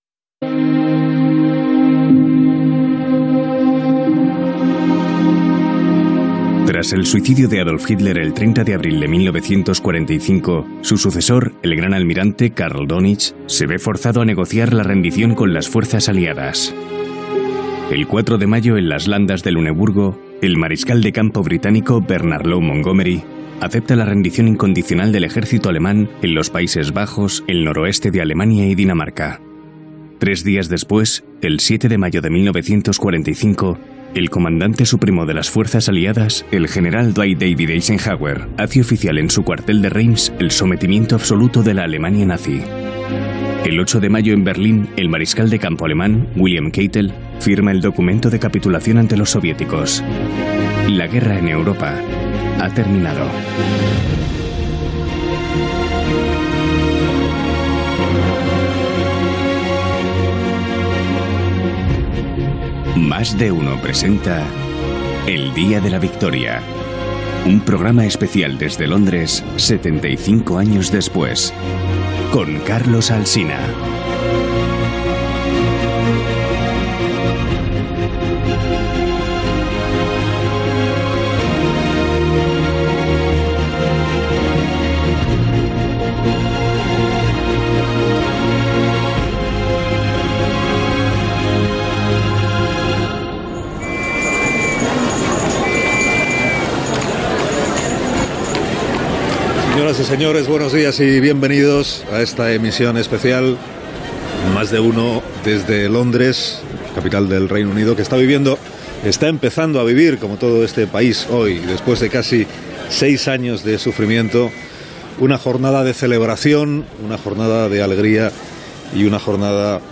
4da6dac8e8c72fc0c71f1492e072f968247d790a.mp3 Títol Onda Cero - Más de uno Emissora Onda Cero Barcelona Cadena Onda Cero Radio Titularitat Privada estatal Nom programa Más de uno Descripció Espai "El día de la victoria", situat a Londres, recordant el 75è aniversari de la victòria aliada a la Segona Guerra Mundial. Fragment d'una ficció sonora que recrea una tranmissió feta aquell dia de 1945 a la ciutat de Londres.